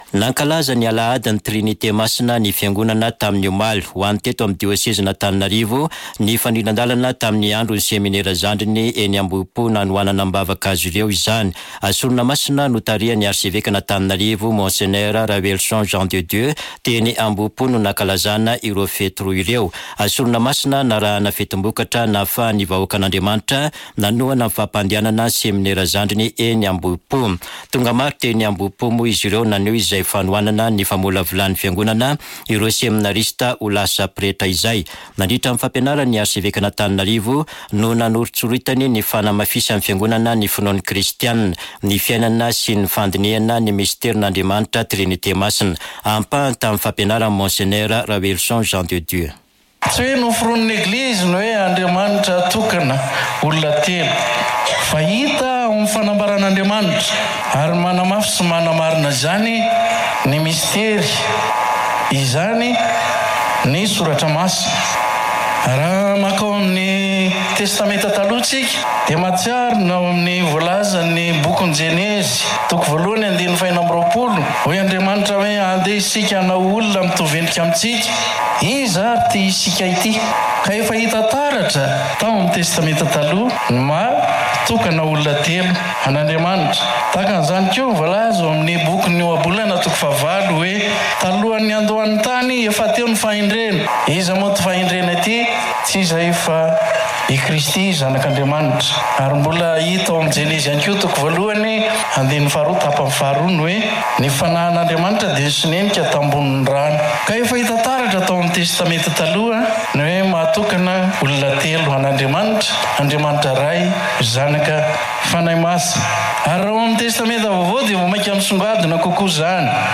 [Vaovao maraina] Alatsinainy 27 mey 2024